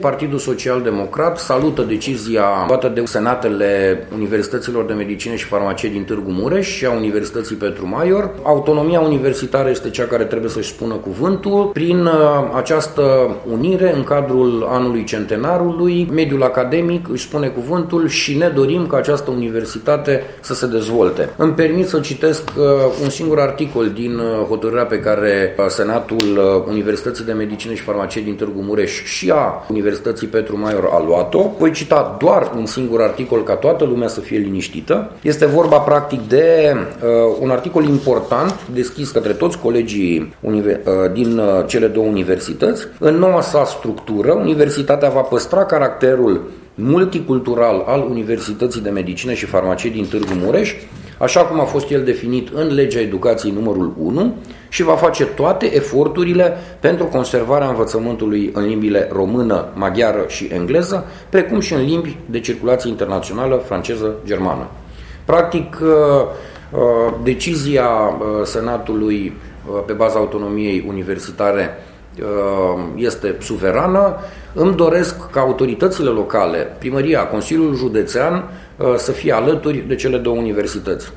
Deputatul de Mureș, Florin Buicu, președinte al Comisiei pentru Sănătate și Familie din Camera Deputaților, a ținut să precizeze că noua universitate își va păstra caracterul multicultural: